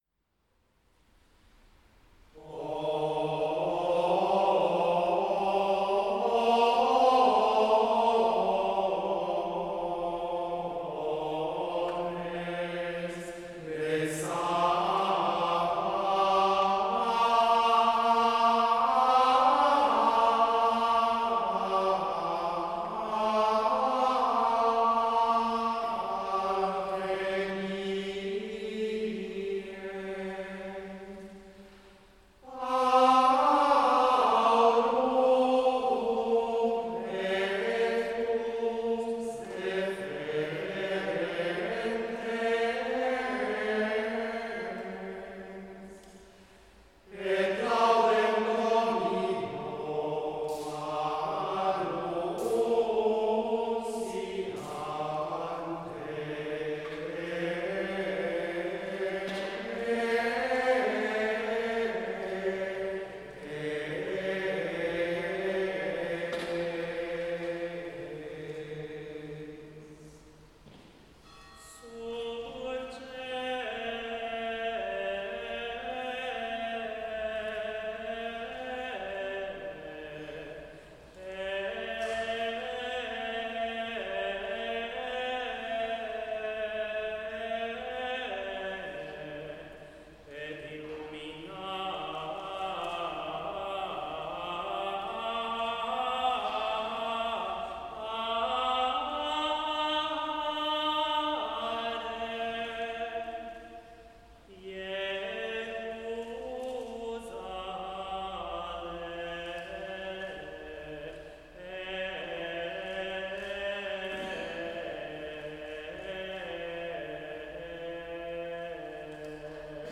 The gregorian chant
Being one of the most ancient musical traditions of the Western World, gregorian chant constitutes a form of sung prayer.